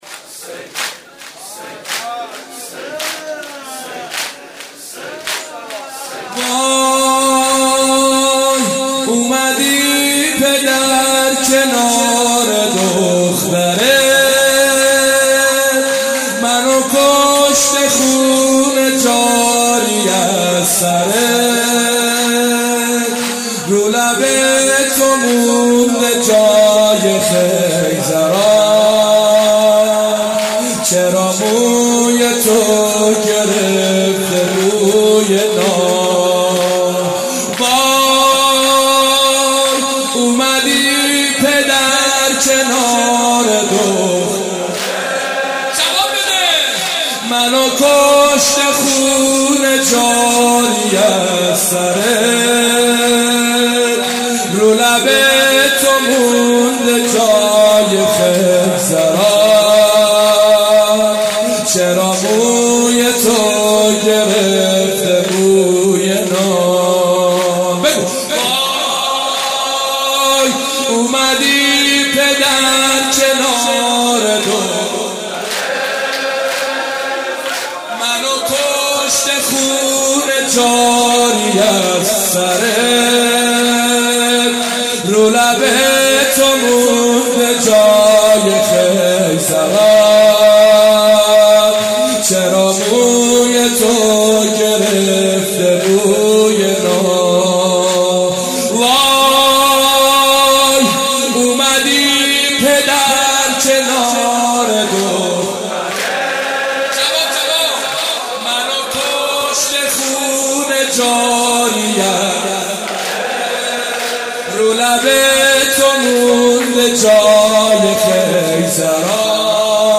دعای کمیل